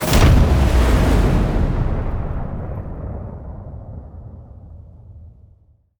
goal_impact.ogg